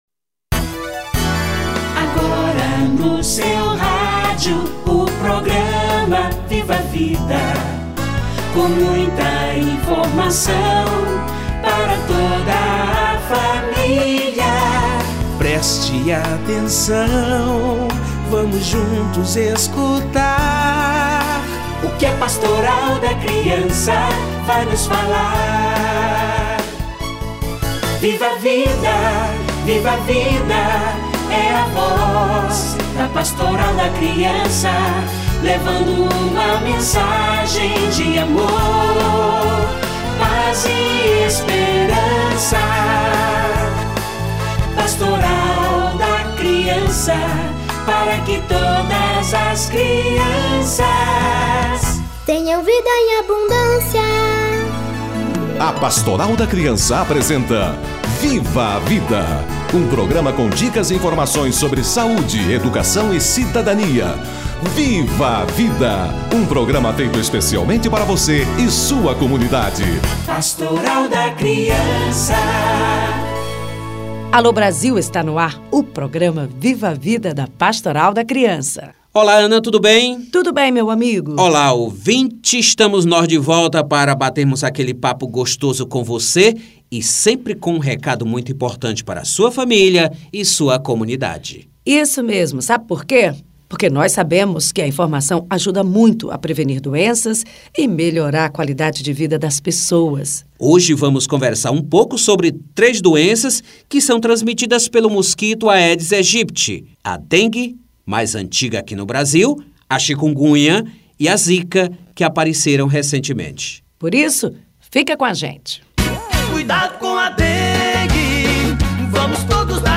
Dengue, Chinkunguya e Zika - Entrevista